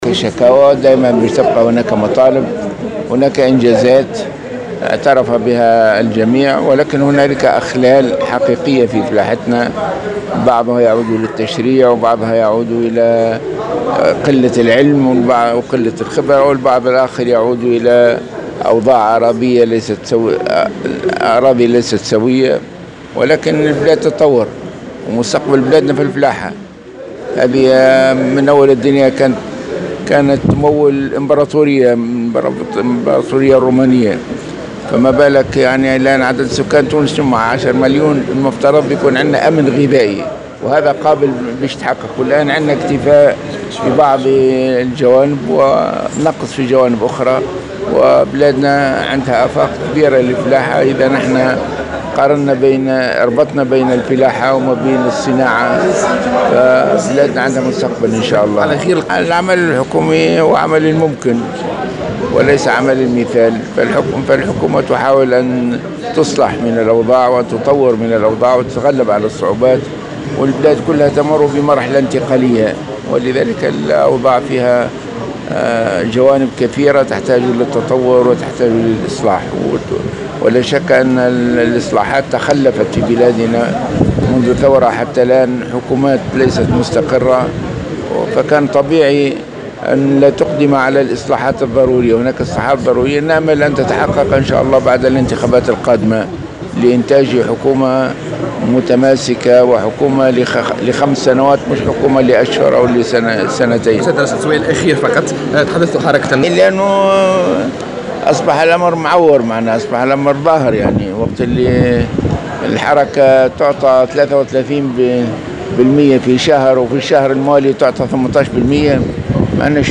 وجاءت تصريحاته على هامش اشراف رئيس الحكومة بتونس العاصمة اليوم على موكب الاحتفال اليوم بالوطني للفلاحة و الصيد البحري، واكبته مراسة "الجوهرة أف أم" .